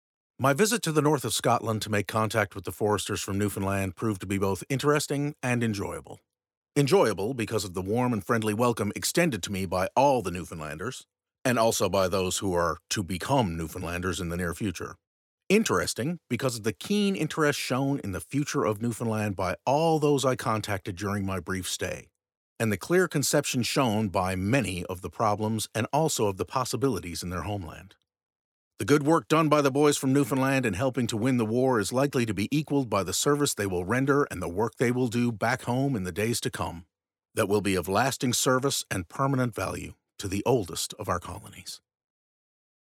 MESSAGE FROM GOVERNOR OF NEWFOUNDLAND, SIR GORDON MACDONALD
Message-from-the-Governor-Sir-Gordon-MacDonald.mp3